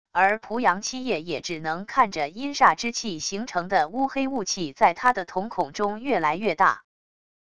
而濮阳七夜也只能看着阴煞之气形成的乌黑雾气在他的瞳孔中越来越大wav音频生成系统WAV Audio Player